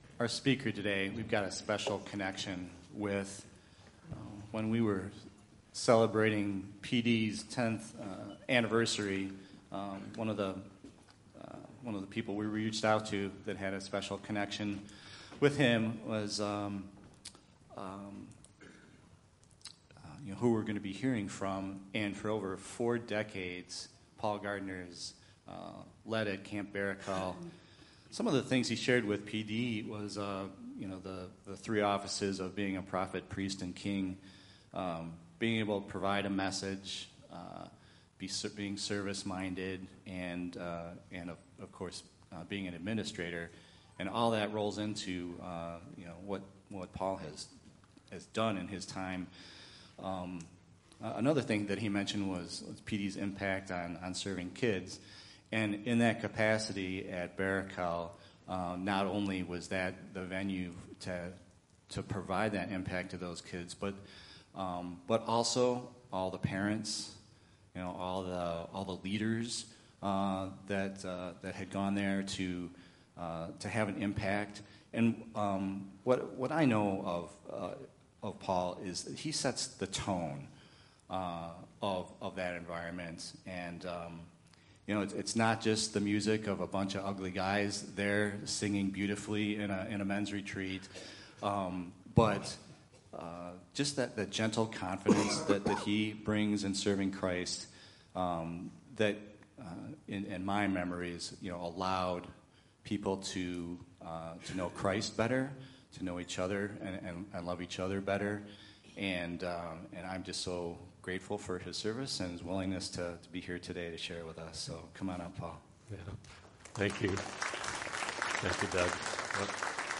Oakwood Community Church Message Podcast | Oakwood Community Church
There are no online notes for this sermon.